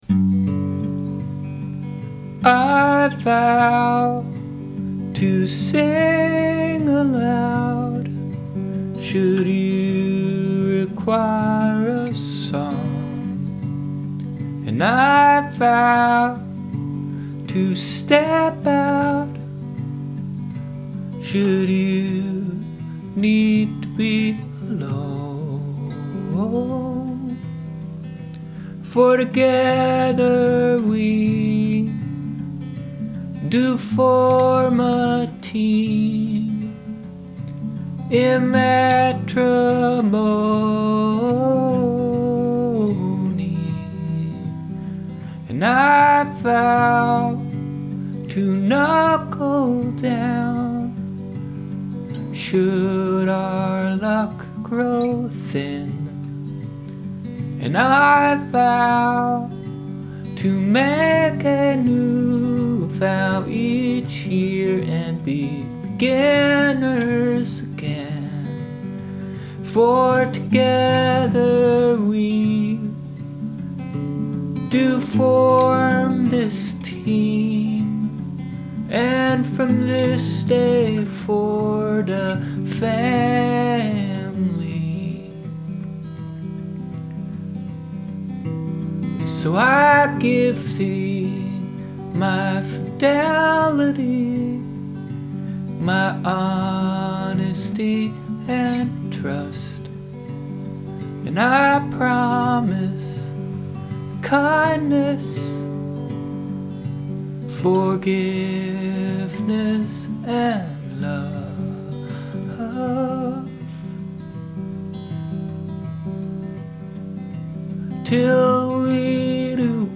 verse 1st part G, E, D, G
verse 2nd part Am, G, D
verse verse verse